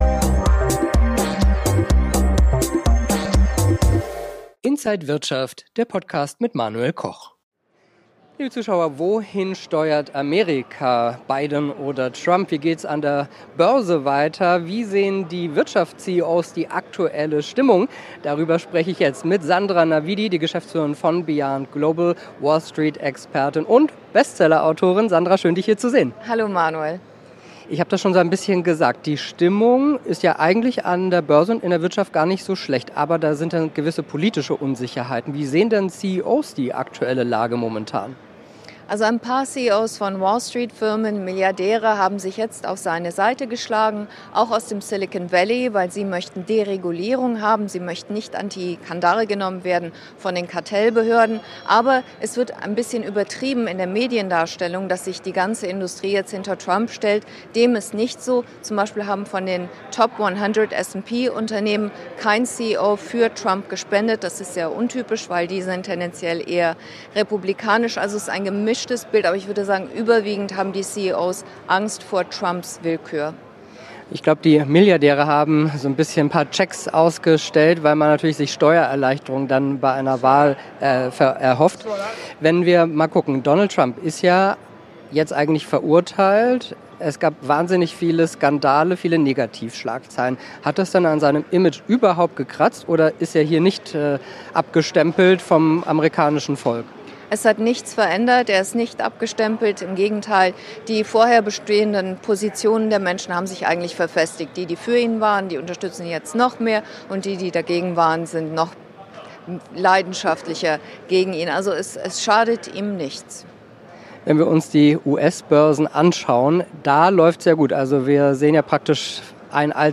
Interview
New York Stock Exchange an der Wall Street und auf